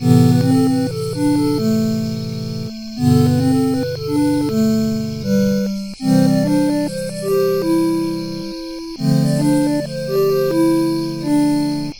addded crappy night music.